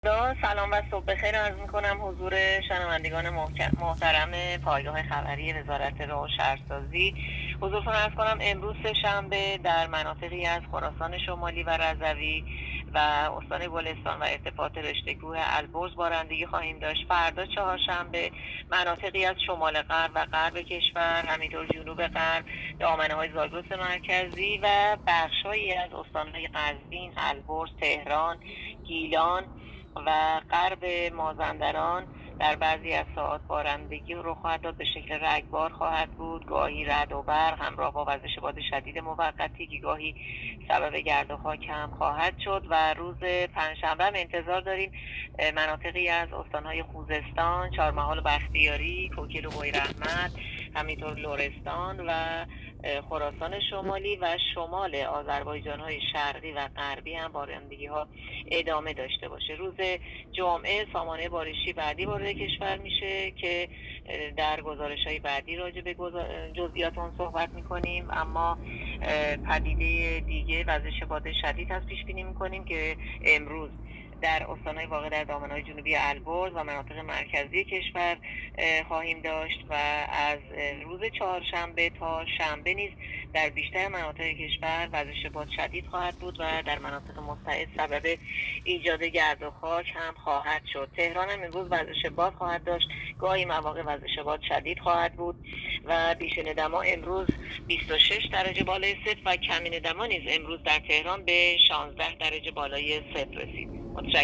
گزارش رادیو اینترنتی پایگاه‌ خبری از آخرین وضعیت آب‌وهوای ۱۲ فروردین؛